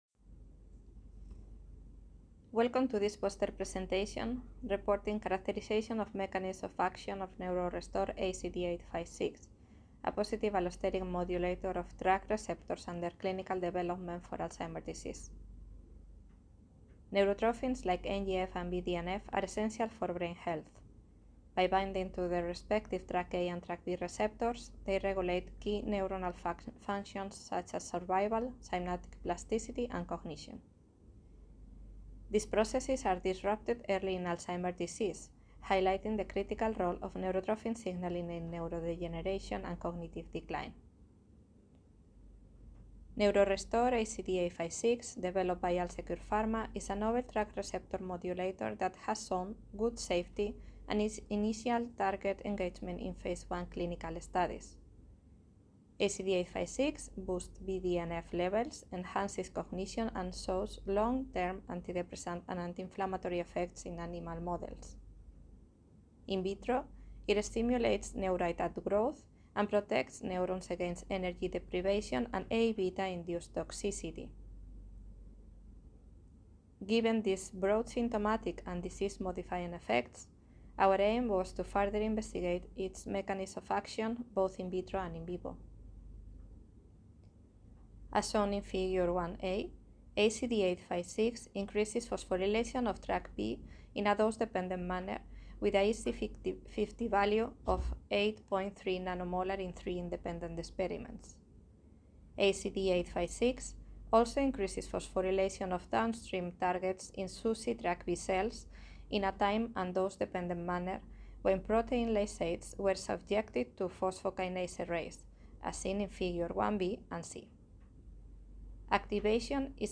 Scientific presentation of preclinical data on NeuroRestore ACD856 demonstrating its mechanism-of action, as presented at the international conference AD/PD 2026, in Copenhagen on March 17-21.